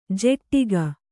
♪ jeṭṭiga